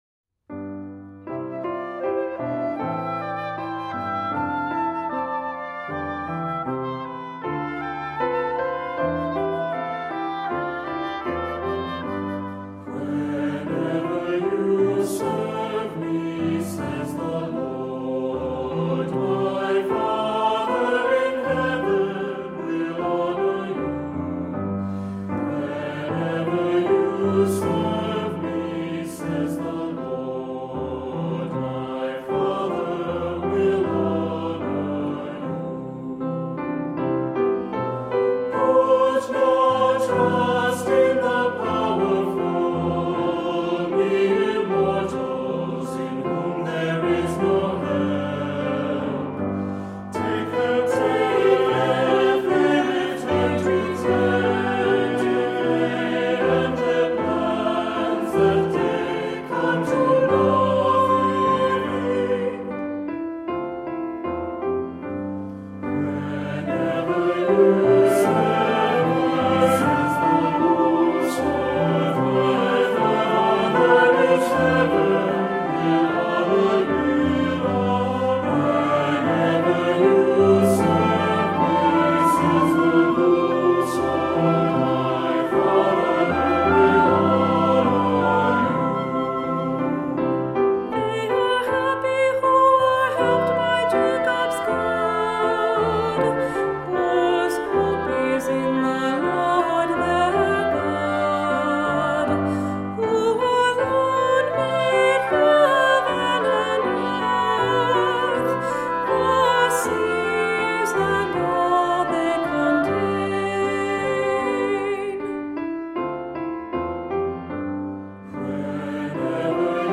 Voicing: 2-part Choir, assembly, cantor